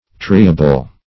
Triable \Tri"a*ble\, a. [From Try.]